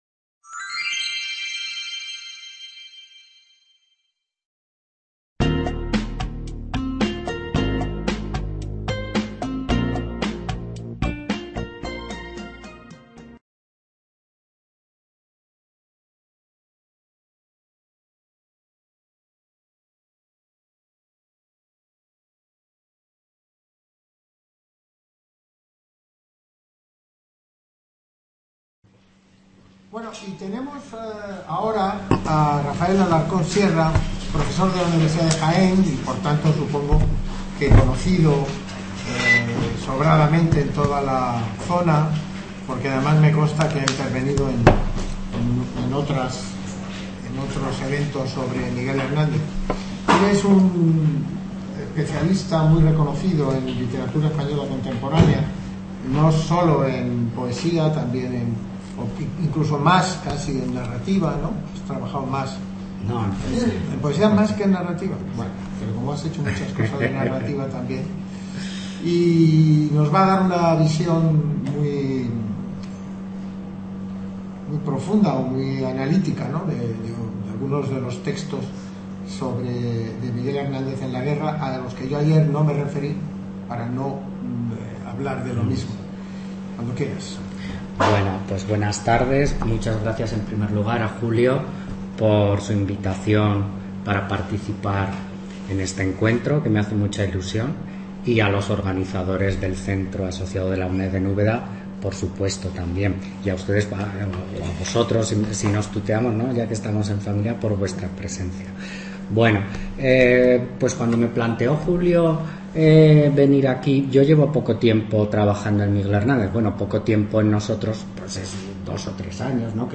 ponencia